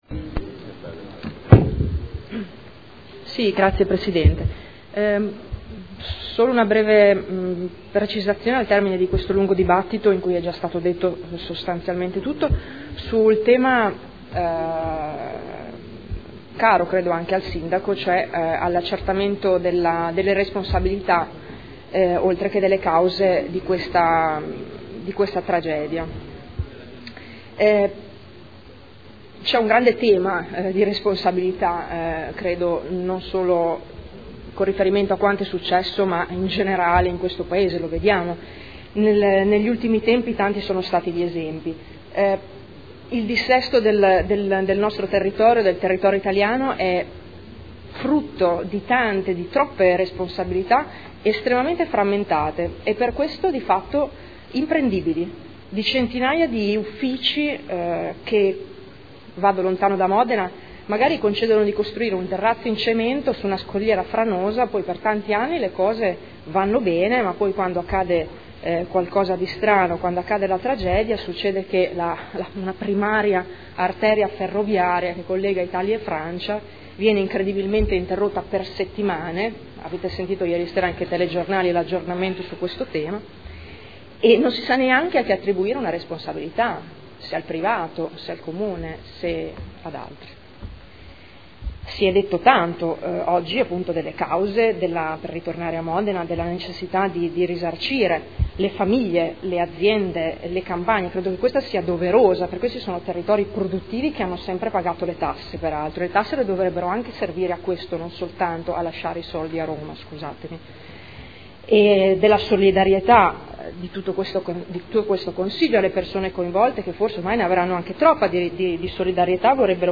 Giuliana Urbelli — Sito Audio Consiglio Comunale
Seduta del 30/01/2014. Dibattito su interrogazioni riguardanti l'esondazione del fiume Secchia.